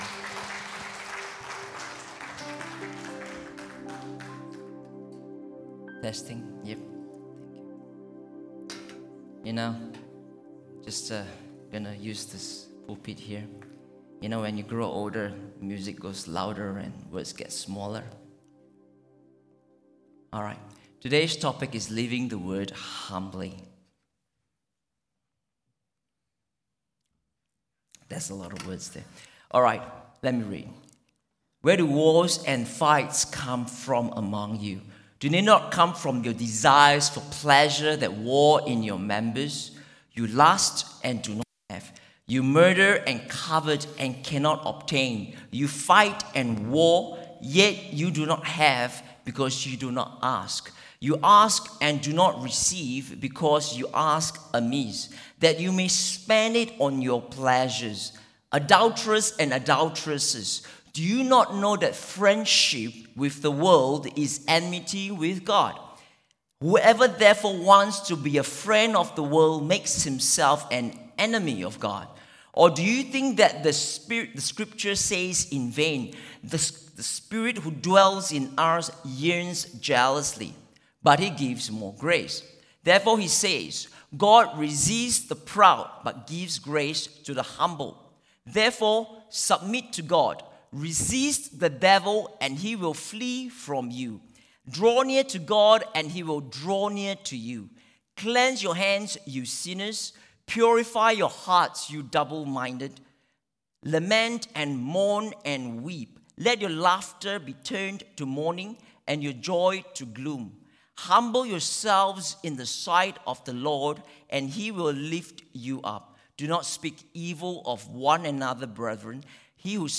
English Worship Service - 26th March 2023
Sermon Notes